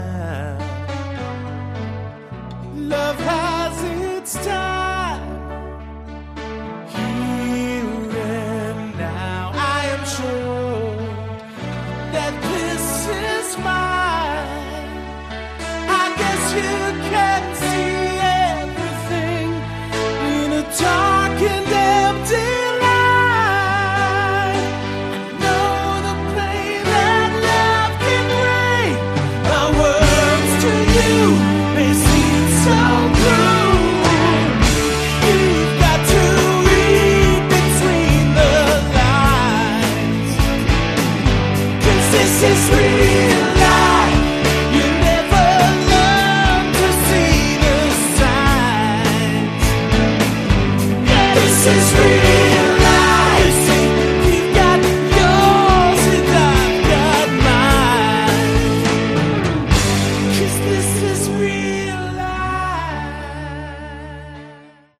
Category: AOR
vocals, guitar, bass
keyboards, vocals
drums